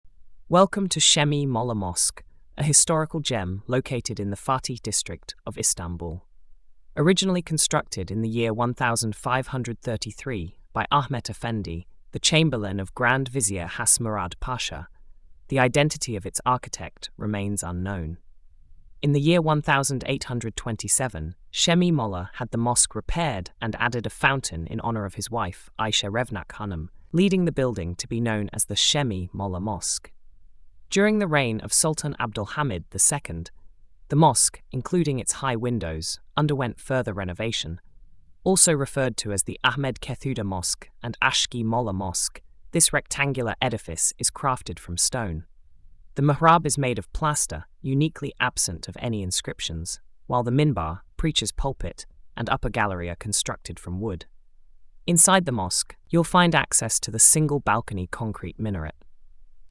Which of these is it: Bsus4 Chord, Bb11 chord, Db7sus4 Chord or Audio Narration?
Audio Narration